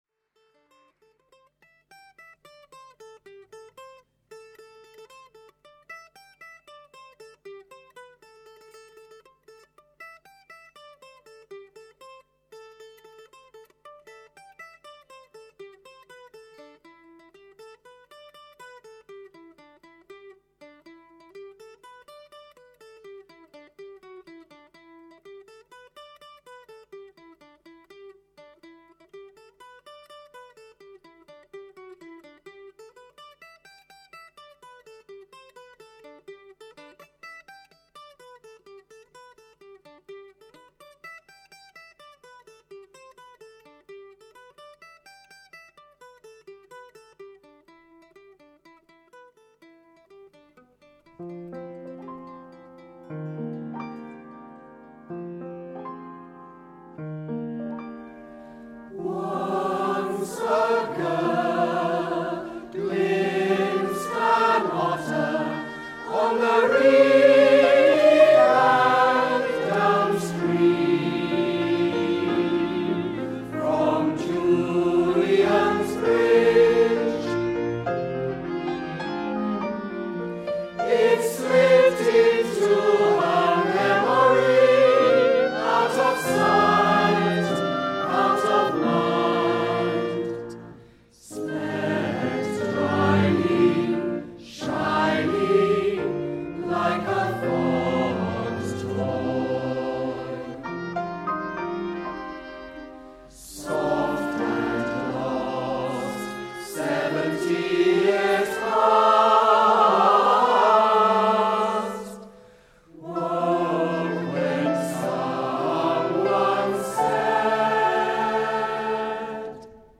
WCT joined other singers to perform the choral works as part of Otter – Lutra, Lutra on the Stour, in Wimborne Minster and Guildford Cathedral.